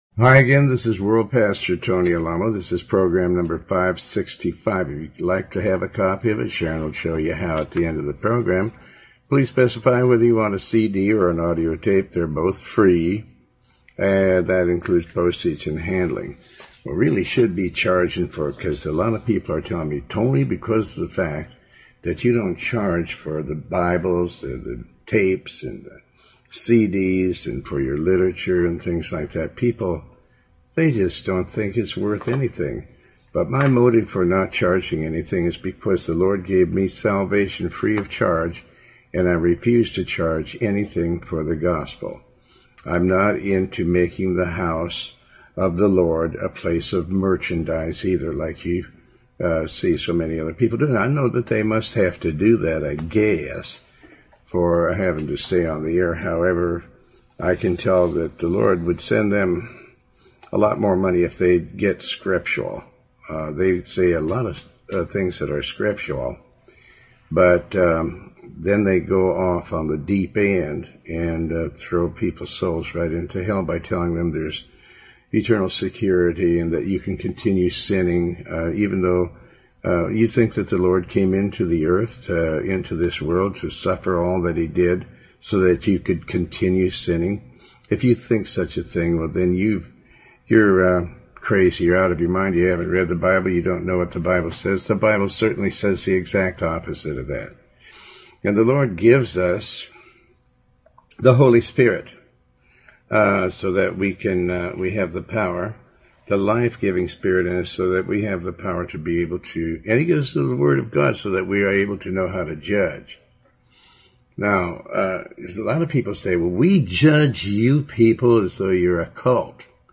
Talk Show Episode, Audio Podcast, Tony Alamo and So many people make the house of the Lord a house of merchandise.